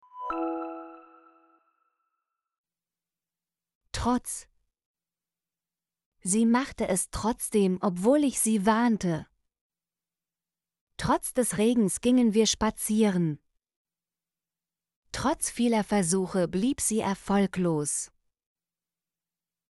trotz - Example Sentences & Pronunciation, German Frequency List